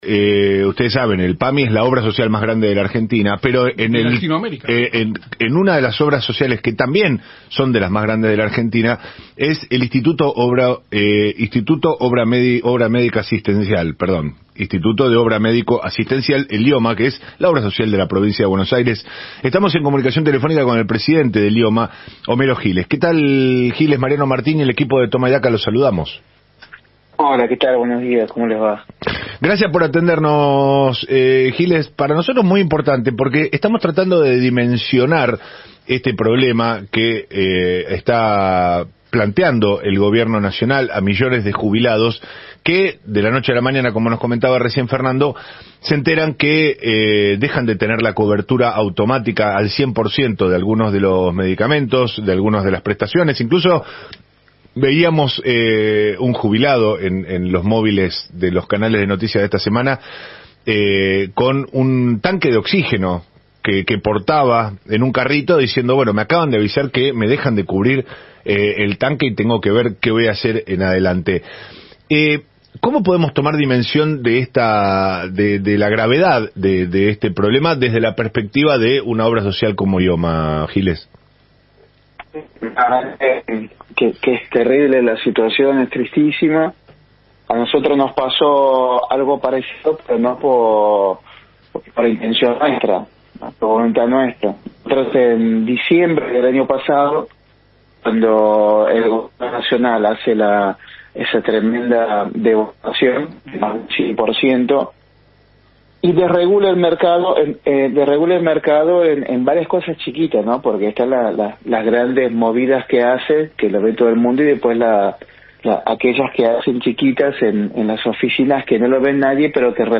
Homero Giles, presidente de IOMA, conversó en AM 750, en el programa “Toma y Daca”